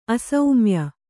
♪ asaumya